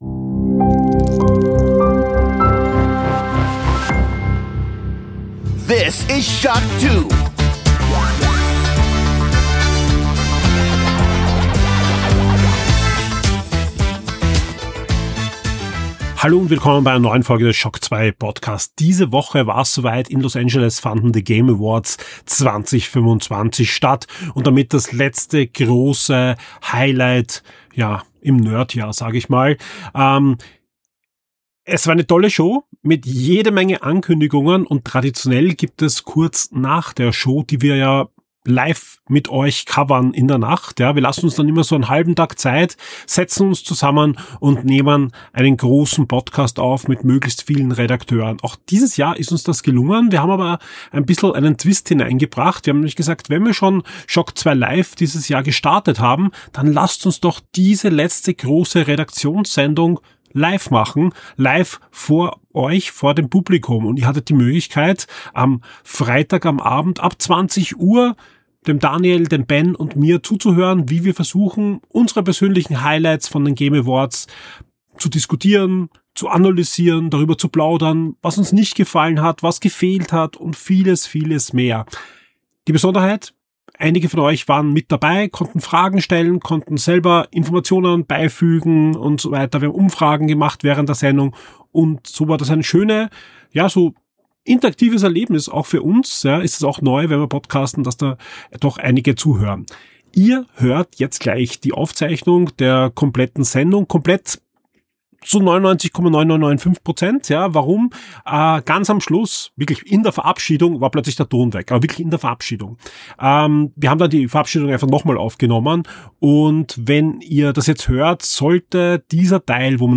Dazu gibt’s persönliche Highlights, kleine Aufreger und den üblichen SHOCK2-Nerd-Talk mit Augenzwinkern.